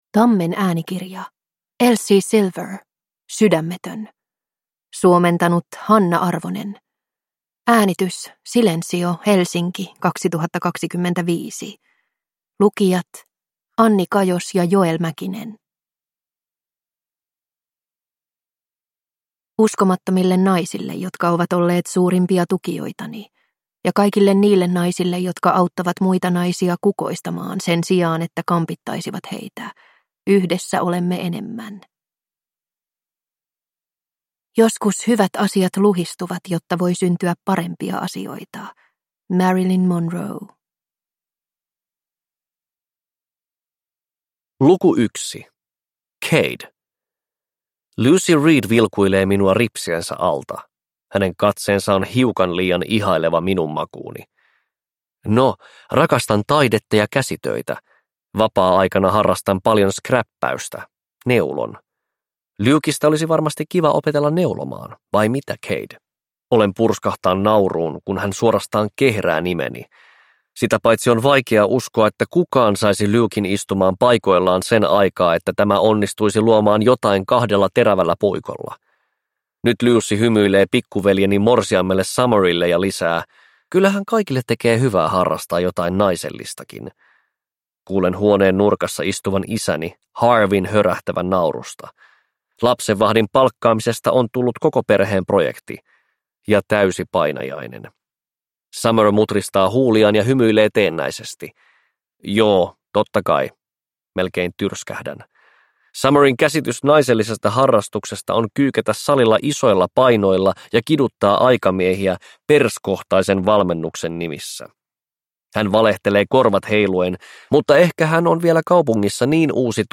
Sydämetön (ljudbok) av Elsie Silver | Bokon